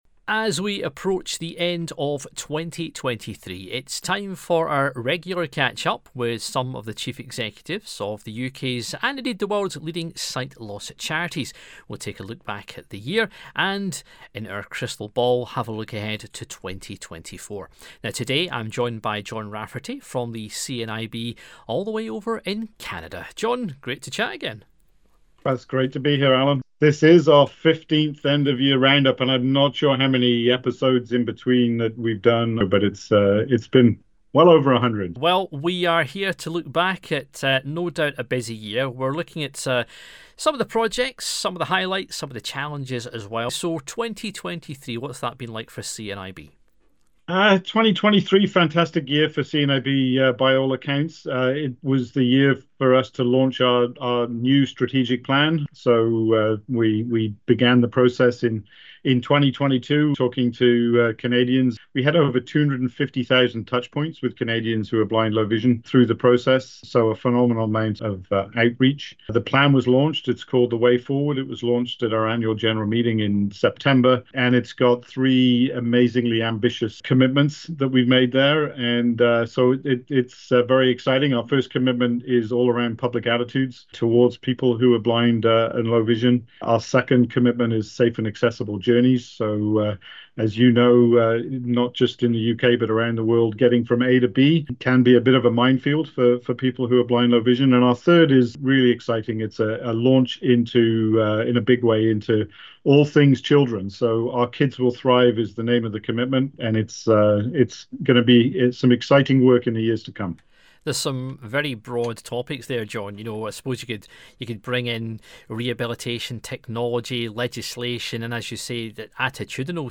talks with the Chief Executives of some of the world’s leading sight loss charities.